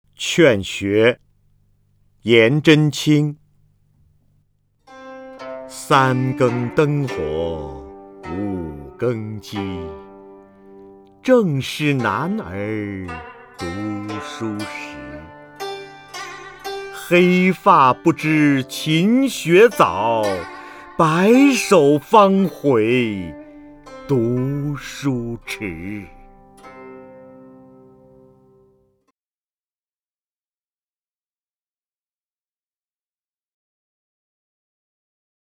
瞿弦和朗诵：《劝学》(（唐）颜真卿) （唐）颜真卿 名家朗诵欣赏瞿弦和 语文PLUS
（唐）颜真卿 文选 （唐）颜真卿： 瞿弦和朗诵：《劝学》(（唐）颜真卿) / 名家朗诵欣赏 瞿弦和